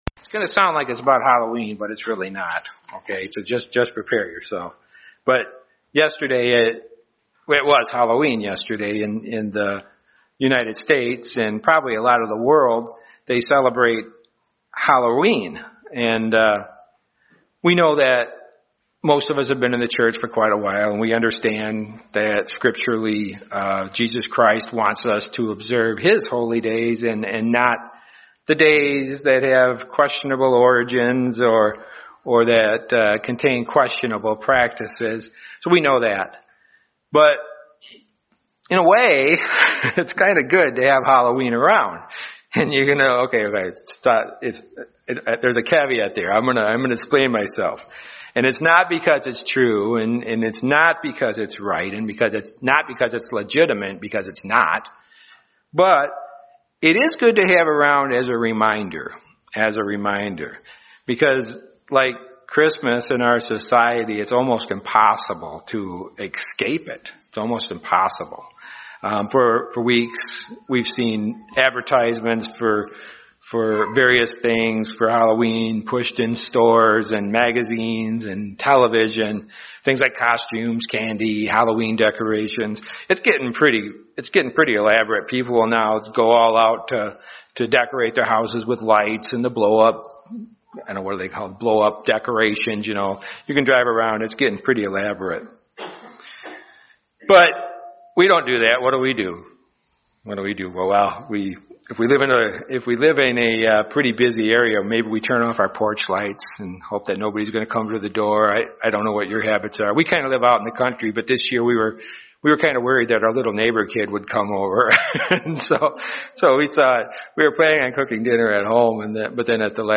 Christians should be livng a very different way of life from those around them. But it's not easy to come out of the world. In this sermon we'll see how hard it was for the early church to do this and how hard it can be for us.